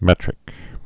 (mĕtrĭk)